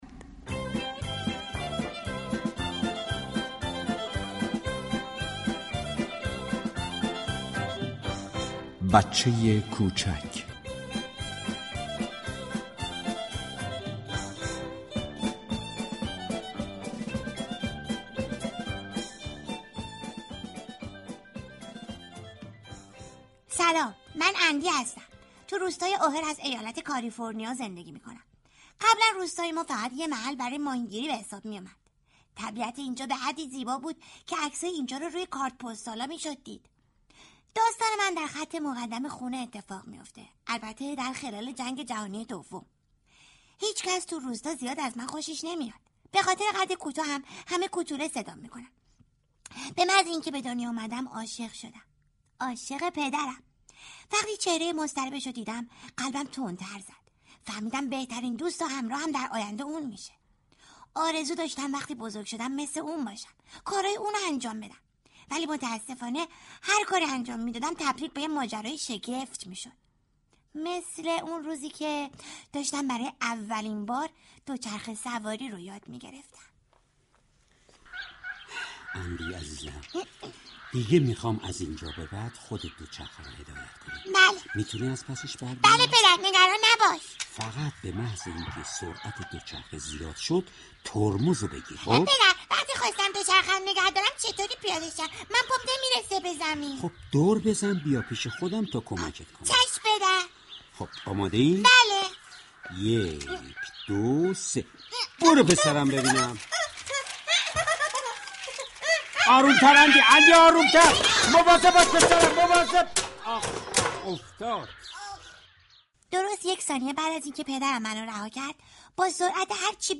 همزمان با فرا رسیدن ولادت حضرت عیسی مسیح (ع) ، نمایش رادیویی «بچه كوچك» به كارگردانی